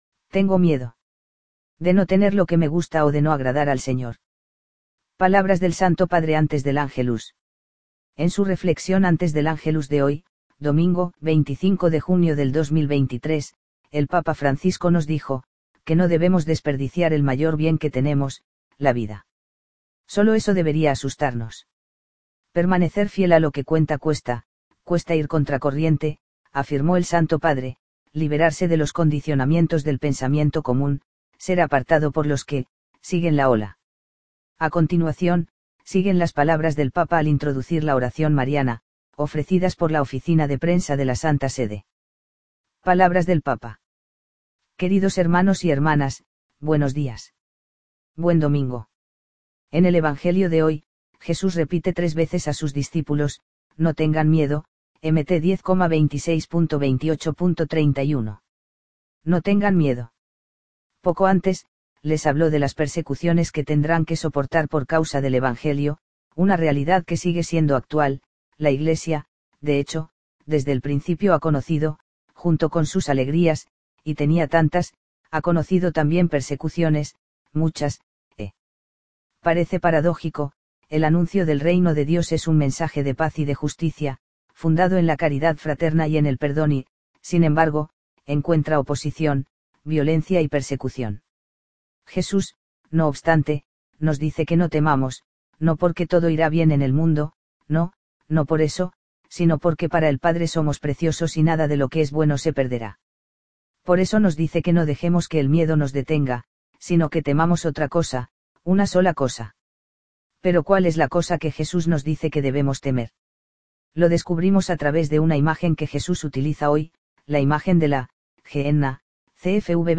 Palabras del santo Padre antes del Ángelus